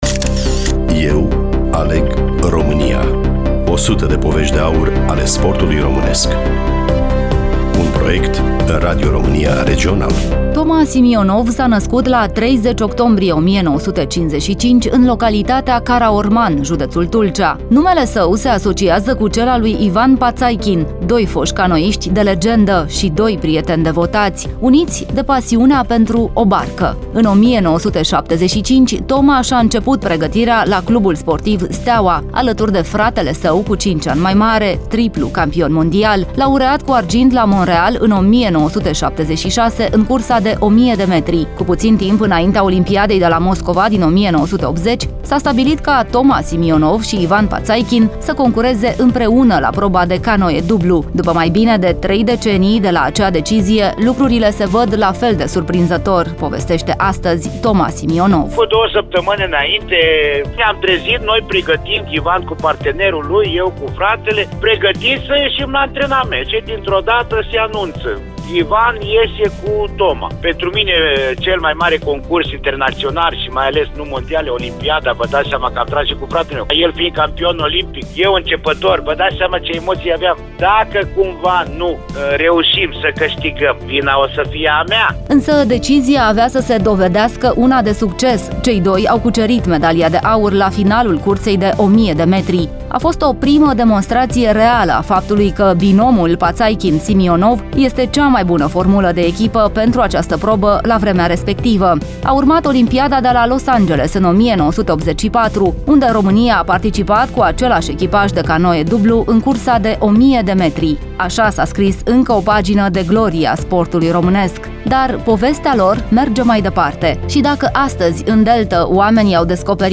Studioul Radio Romania Constanta